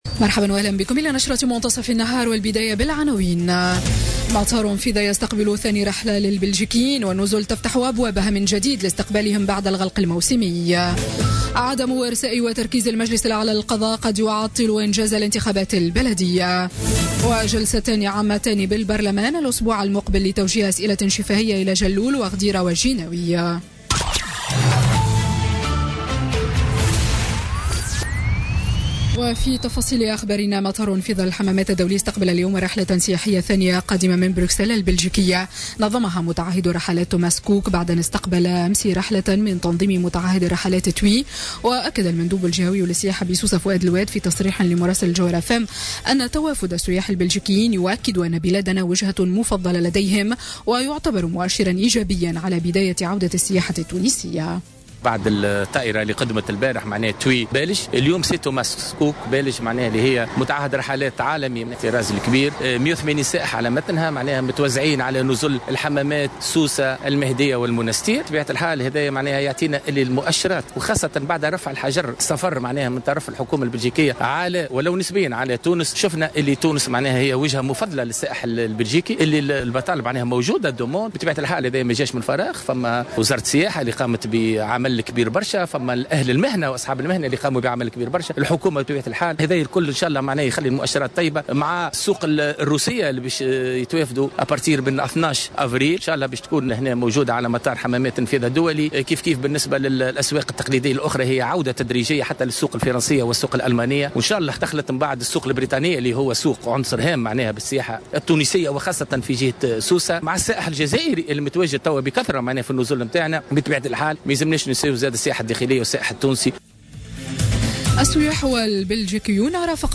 نشرة أخبار منتصف النهار ليوم السبت غرة أفريل 2017